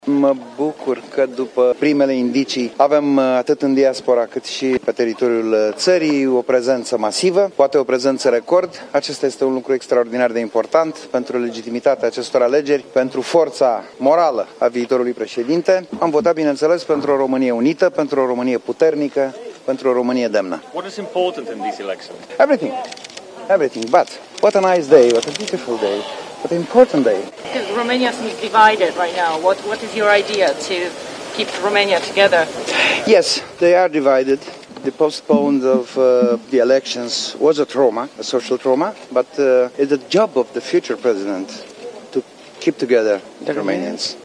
ALEGERI PREZIDENȚIALE 2025, TURUL I. Crin Antonescu, la ieșirea de la vot: „Am votat pentru o Românie unită, pentru o Românie puternică, pentru o Românie demnă” | AUDIO : Europa FM
Candidatul a primit întrebări și de la jurnaliști străini.